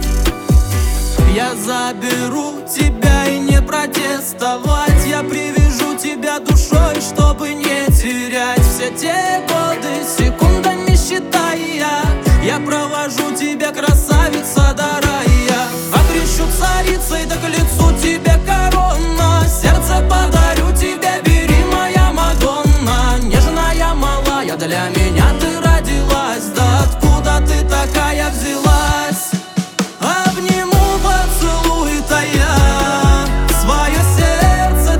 Жанр: Русские
# Поп